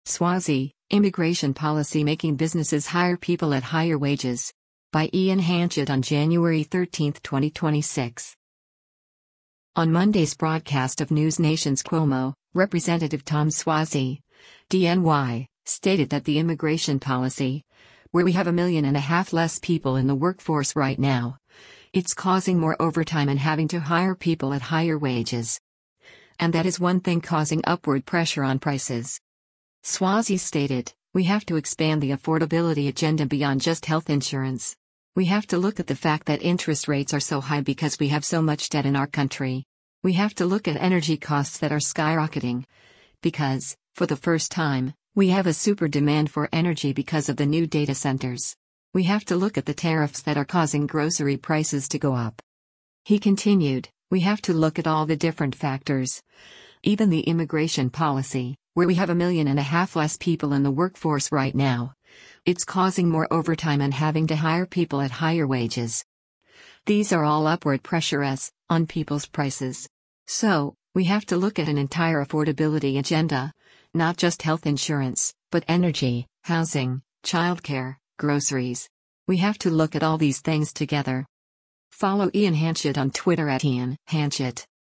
On Monday’s broadcast of NewsNation’s “Cuomo,” Rep. Tom Suozzi (D-NY) stated that “the immigration policy, where we have a million and a half less people in the workforce right now, it’s causing more overtime and having to hire people at higher wages.” And that is one thing causing upward pressure on prices.